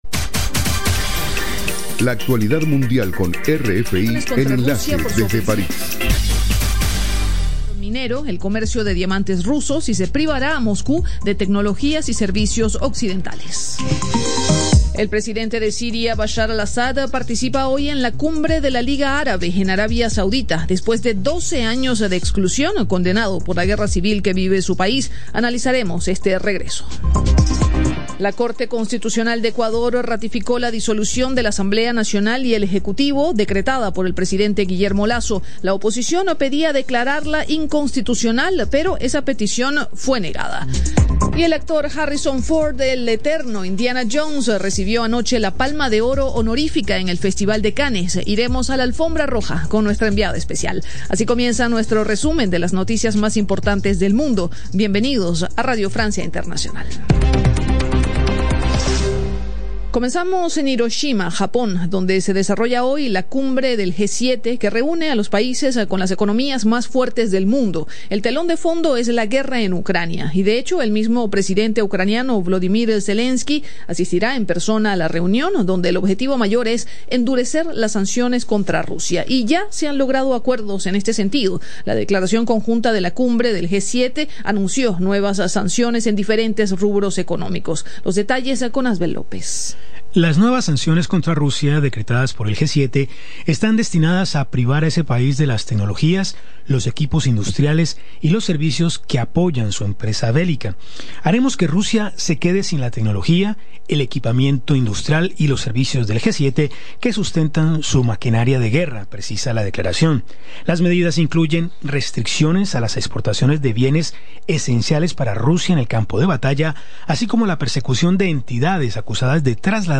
Programa: RFI – Noticiero de las 07:00 Hs.